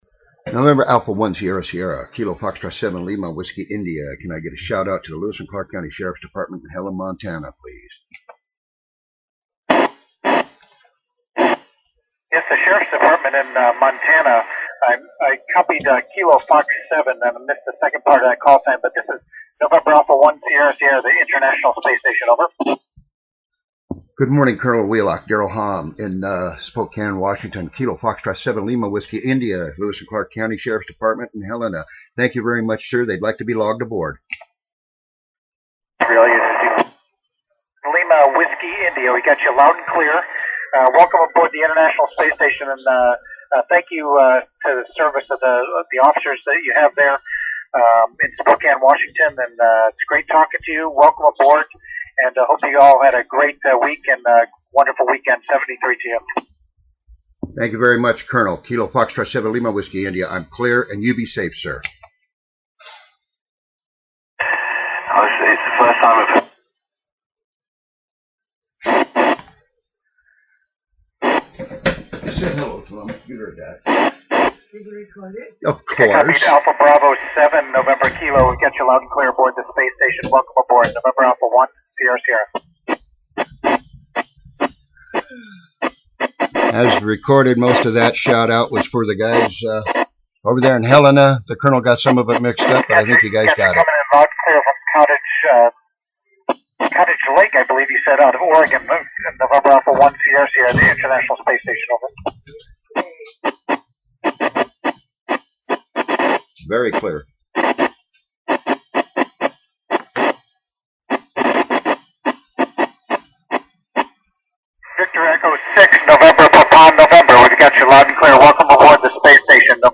contact and shout out made as well as call sign repeted By Col.Wheelock